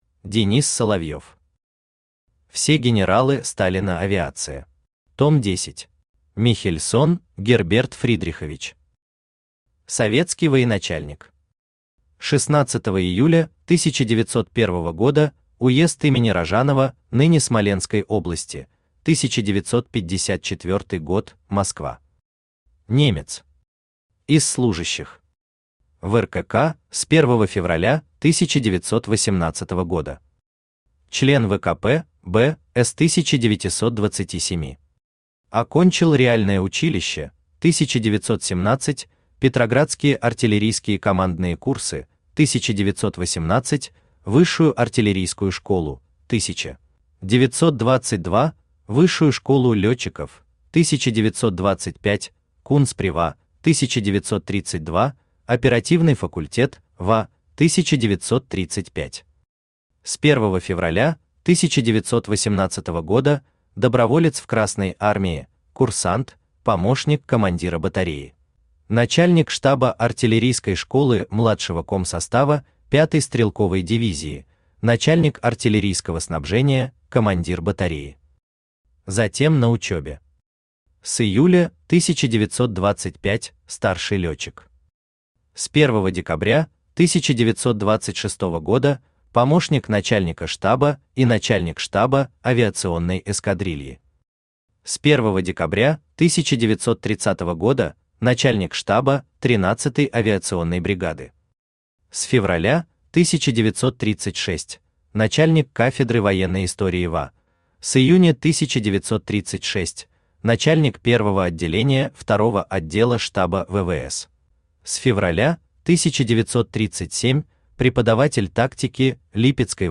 Том 10 Автор Денис Соловьев Читает аудиокнигу Авточтец ЛитРес.